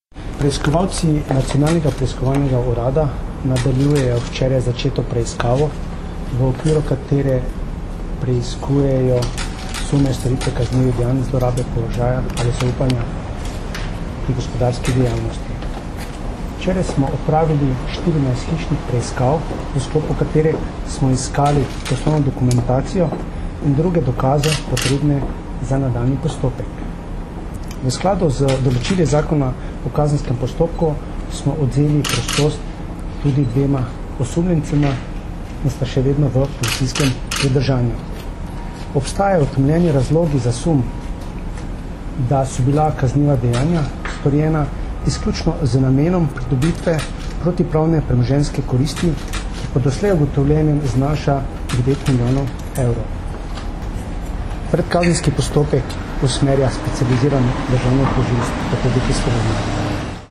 Izjavo za javnost je dal Darko Majhenič, direktor NPU
Zvočni posnetek izjave (mp3)